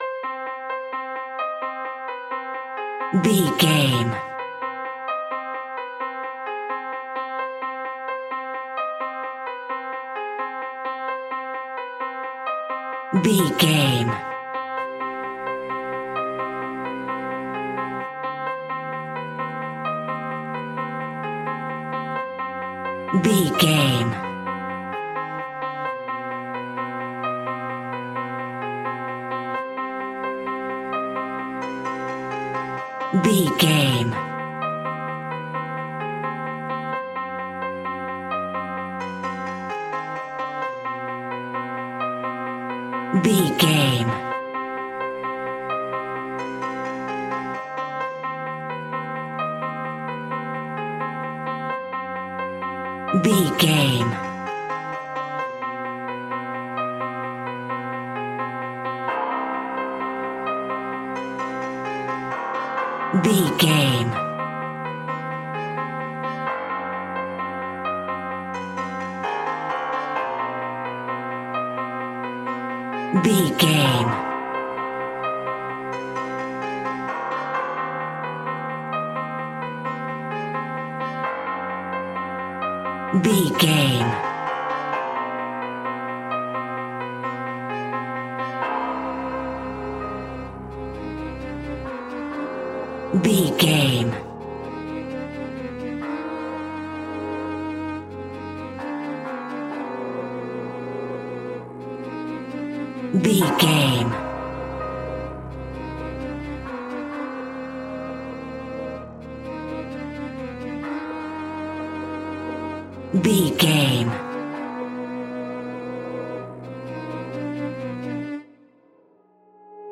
Aeolian/Minor
ominous
dark
suspense
eerie
piano
strings
horror
synth
ambience
pads